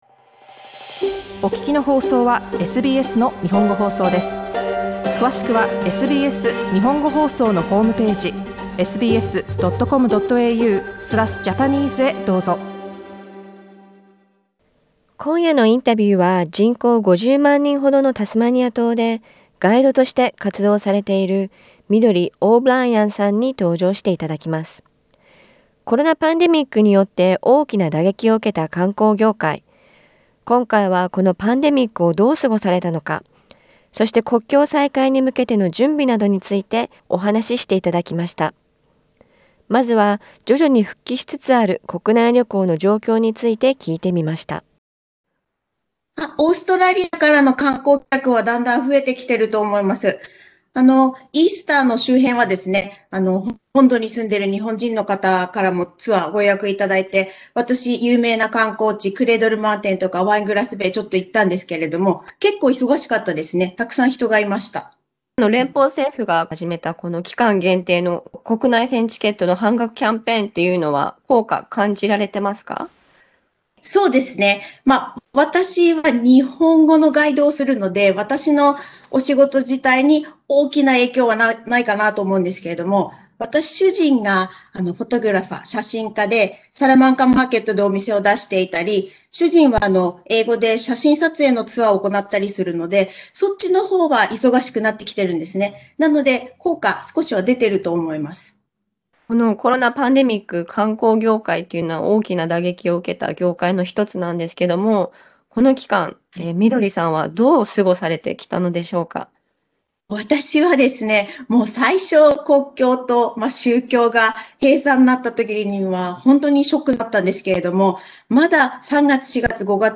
インタビューではウォンバットのレスキューやオーロラについてもお話いただきました。